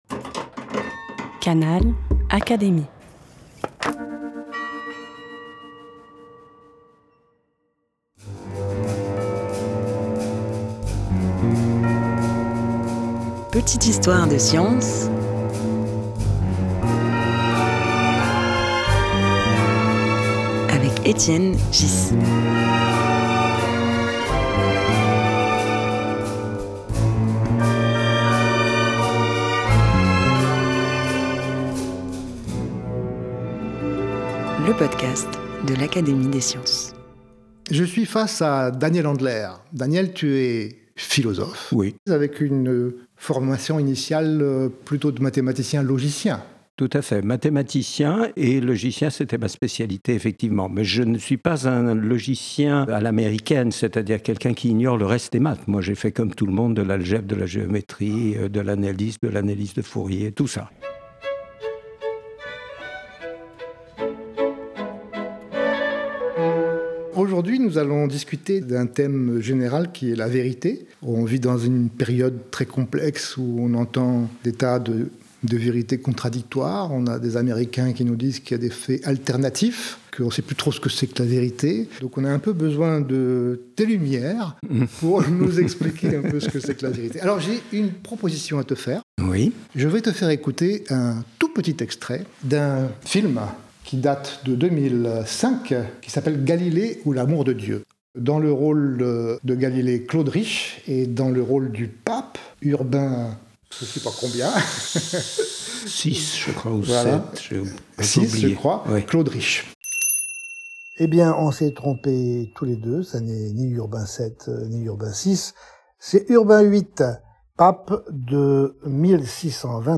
Un podcast animé par Étienne Ghys, proposé par l'Académie des sciences.